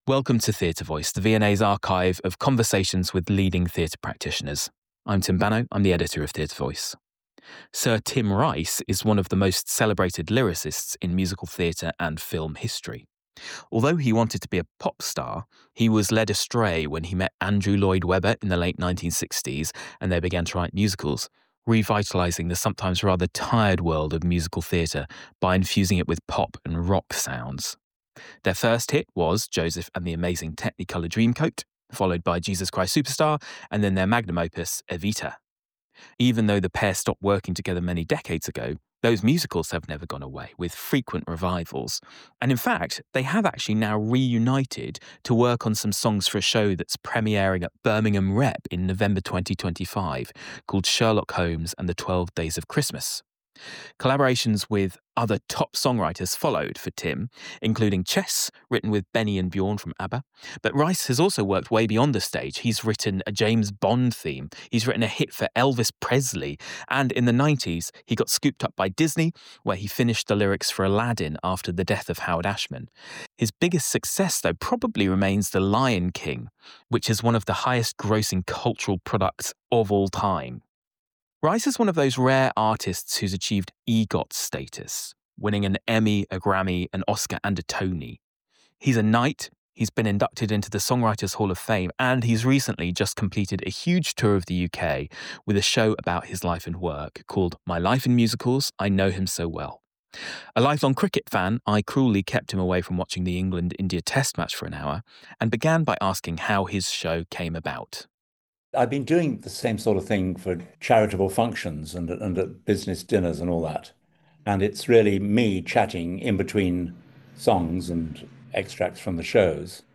INTERVIEW: SIR TIM RICE Sir Tim Rice is one of the most celebrated lyricists in theatre and film.
Recorded on Zoom, 25th July, 2025.